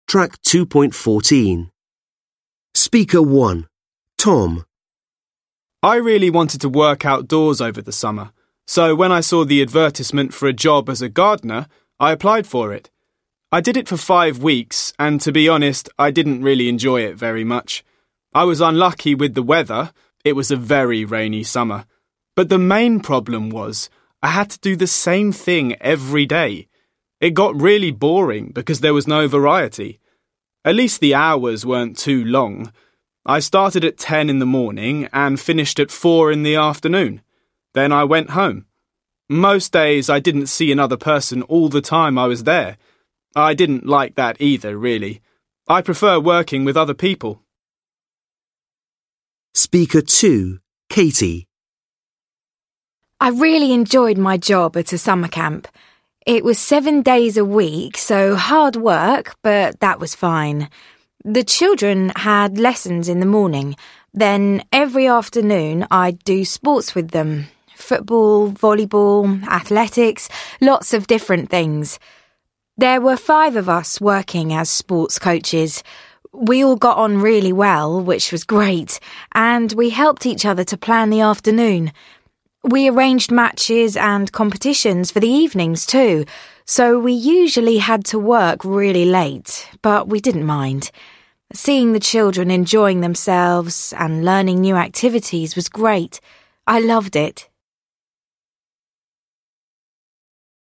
5 (trang 59 Tiếng Anh 10 Friends Global) Now listen to two university students talking about their part-time jobs.